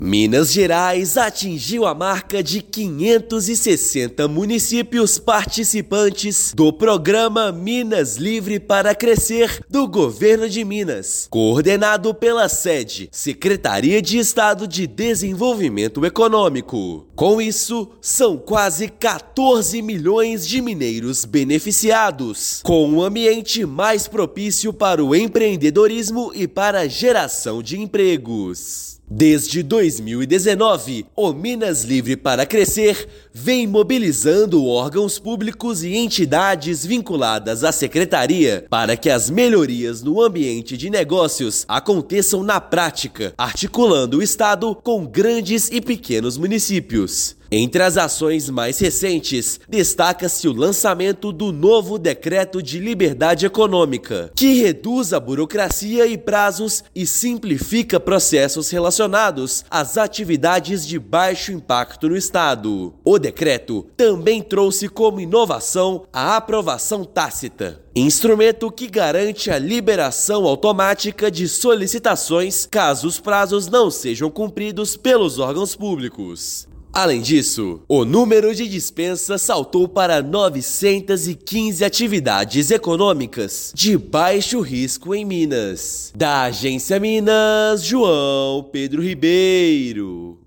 [RÁDIO] Minas Gerais supera a marca de 560 municípios Livres para Crescer
Programa de Liberdade Econômica do Governo de Minas avança em grandes e pequenas cidades. Ouça matéria de rádio.